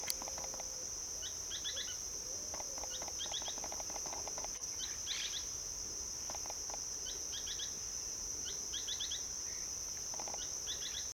Spix´s Spinetail (Synallaxis spixi)
Life Stage: Adult
Condition: Wild
Certainty: Recorded vocal
pijui-plomizo.mp3